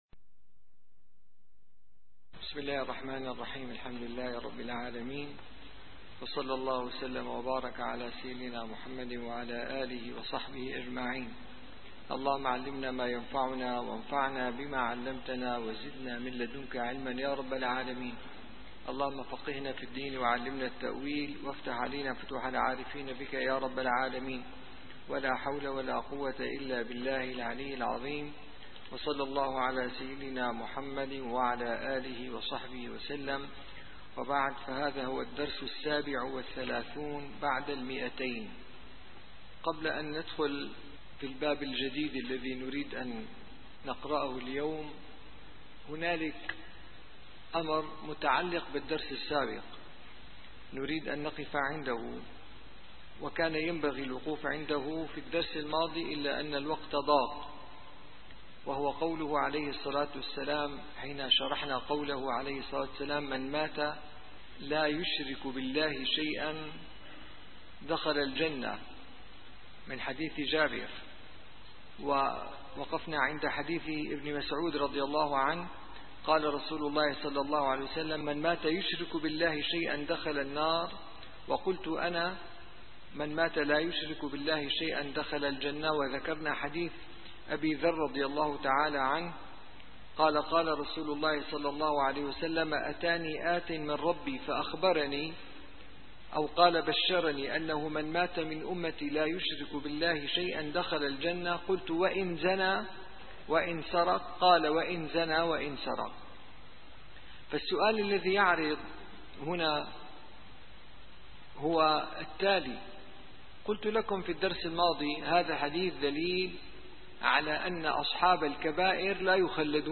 - الدروس العلمية - شرح صحيح البخاري - كتاب الجنائز الحديث 1241 - 1244